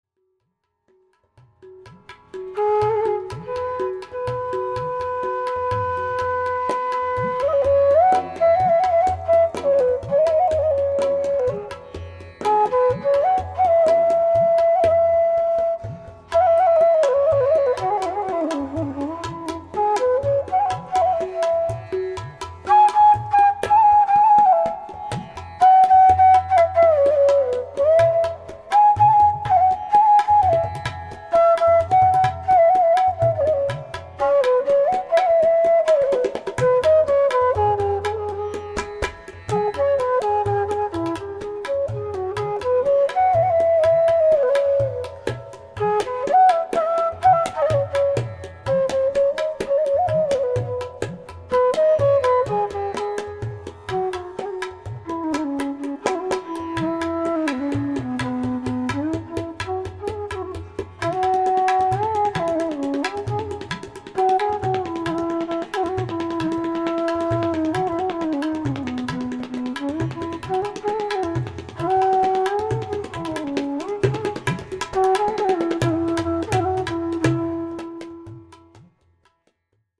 Contemplative bansuri melodies
tabla
Genre: North Indian Classical.
Dadra Tal (6)   7:38